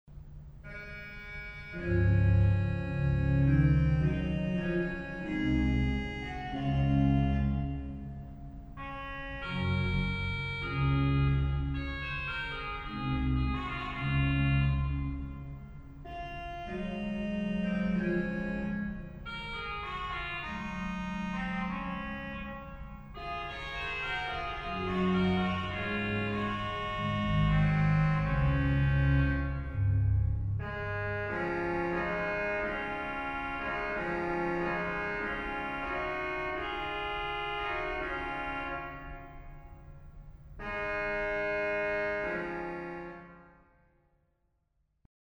Dialogue 1, Trio et Quatuor de Hautbois et de Basson (remplacé ici par la Voix humaine) 2 ; « effet de cors » sur les Trompettes de Grand orgue et d’Echo ; « imitation des petits oiseaux » sur les Nazards seuls touchés une quinte plus bas ; conclusion en decrescendo sur les Flûtes du Positif et de l’Echo.
Positif : Montre 8, Bourdon 8, 1ère et 2e Flûte 8
G.O. : Voix humaine
Récit : Hautbois
Echo : Flûte 8, Bourdon 8
Pédale : Soubasse 16, Flûte 8, Flûte 4
1 La Voix humaine est accompagnée au Positif, le Hautbois à l’Echo.